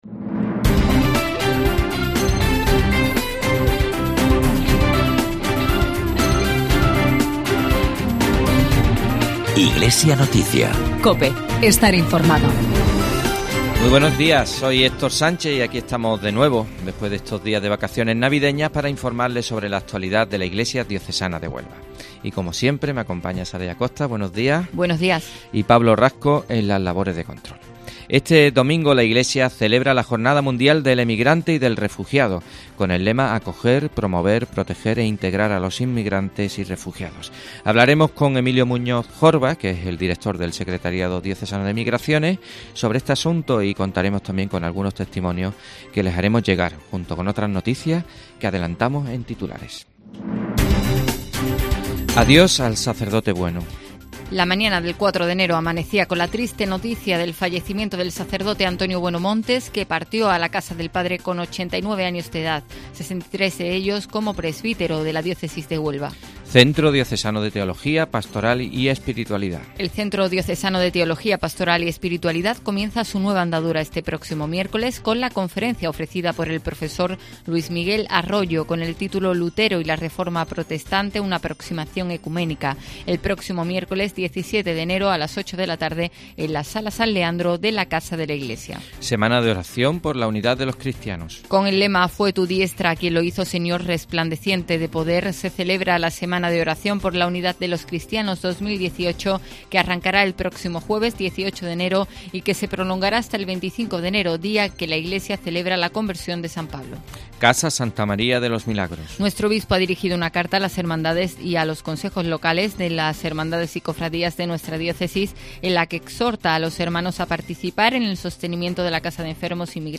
AUDIO: En nuestro informativo diocesano de esta semana la Jornada Mundial del Emigrante y Refugiado